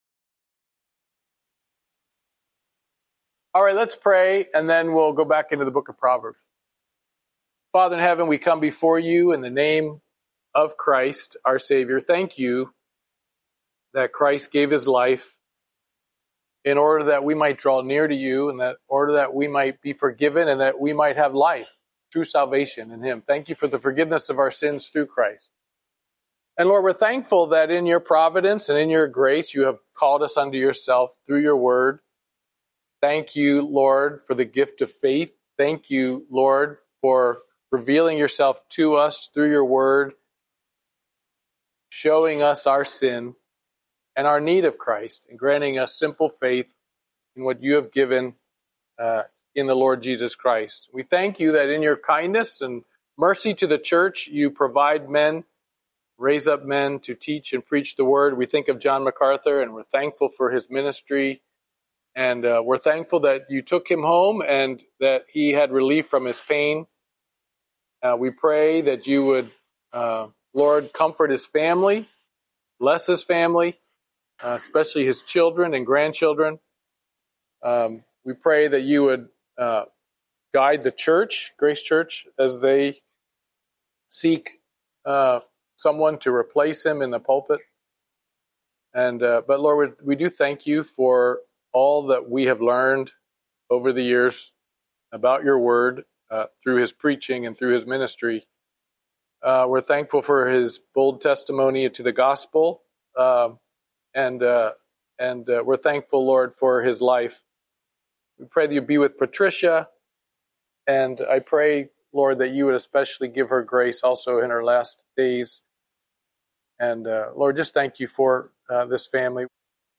Wednesday Morning Bible Study